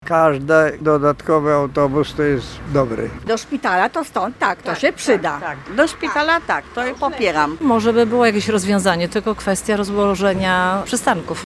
Co mówią o zmianach komunikacyjnych mieszkańcy ulicy Legionów?